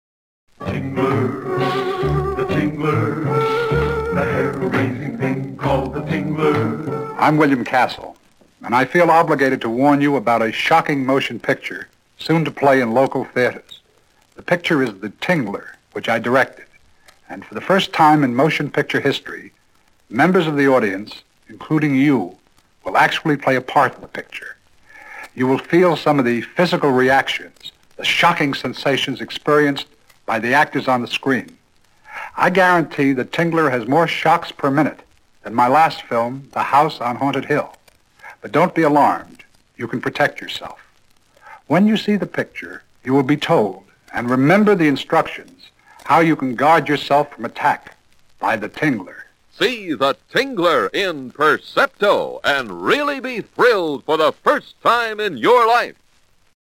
10, 20, 30, and 60 second radio spots
The-Tingler-William-Castle-60-converted.mp3